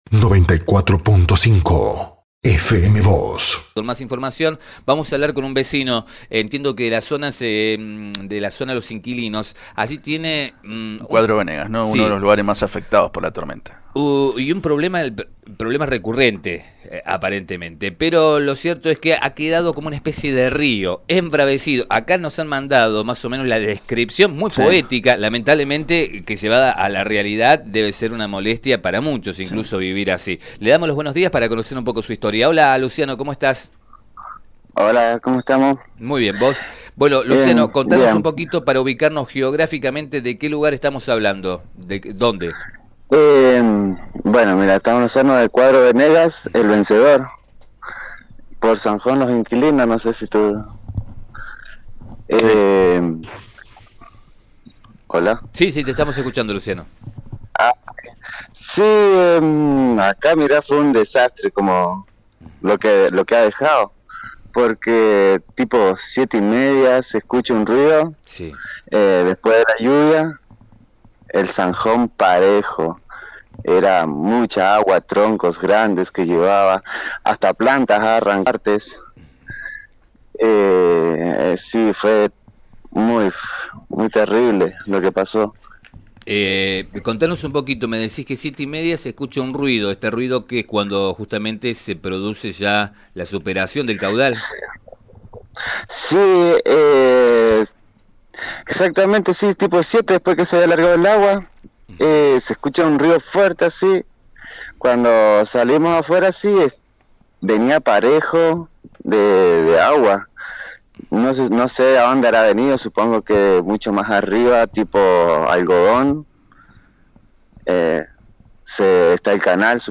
habló al respecto en FM Vos (94.5) y dijo que “fue un desastre lo que ha dejado